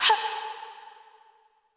K-7 Vocal Hit.wav